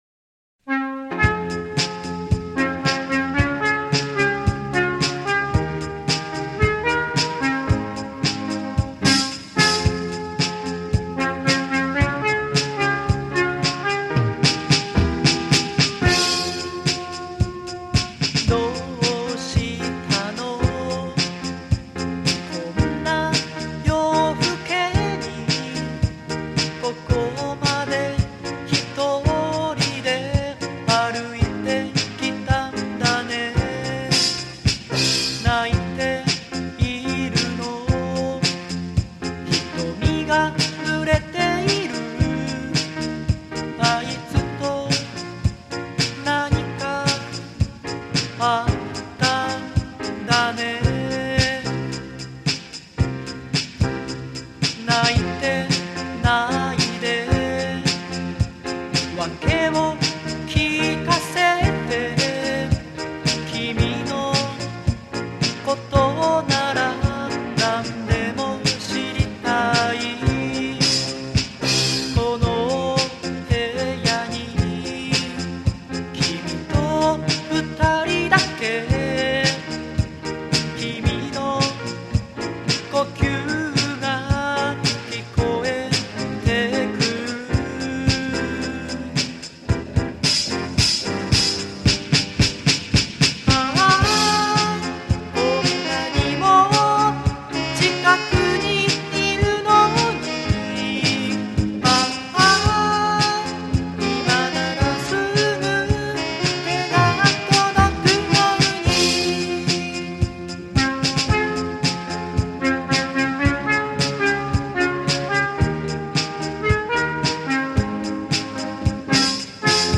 ボーカル・キーボード
ベース・ギター、シーケンサー、ドラムプログラム